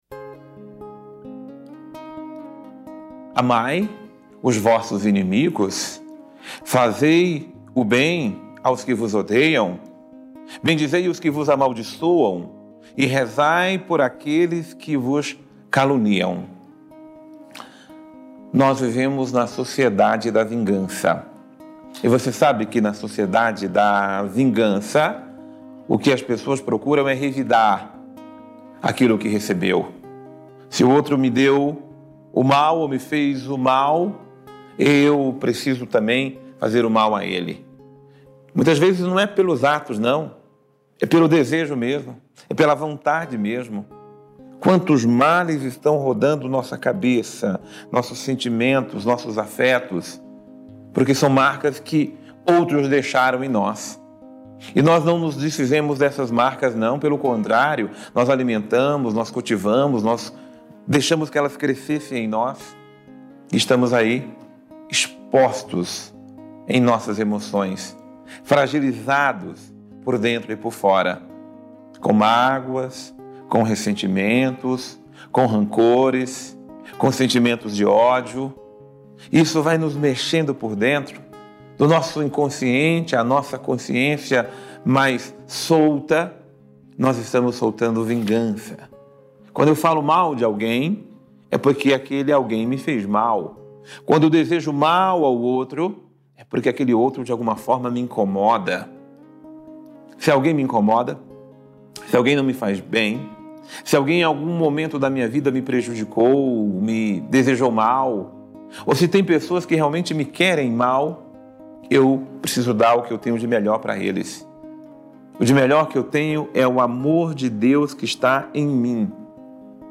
Homilia diária - Façamos o bem aos nossos inimigos